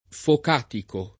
vai all'elenco alfabetico delle voci ingrandisci il carattere 100% rimpicciolisci il carattere stampa invia tramite posta elettronica codividi su Facebook focatico [ fok # tiko ] s. m. (giur.); pl. (raro) ‑ci — non fuocatico